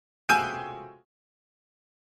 Comedy Piano Chord 6 - Surprise, Single Hit